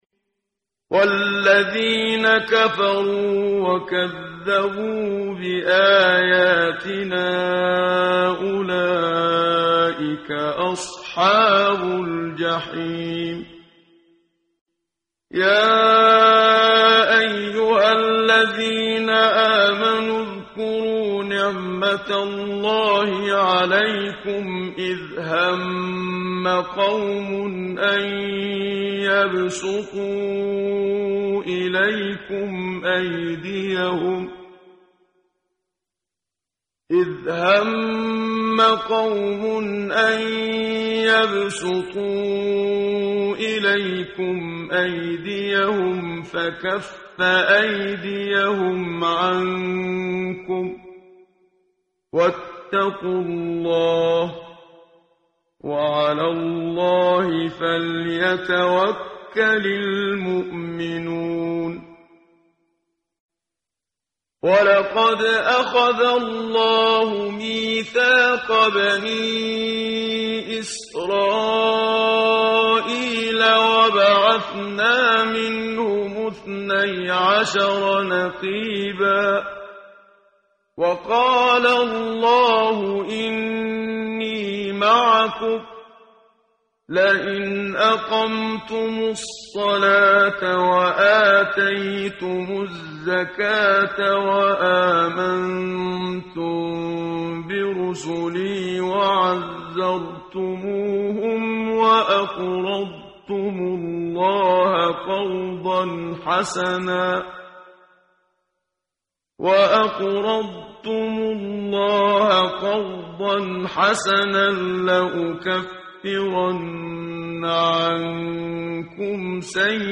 ترتیل صفحه 109 سوره مبارکه المائده (جزء ششم) از سری مجموعه صفحه ای از نور با صدای استاد محمد صدیق منشاوی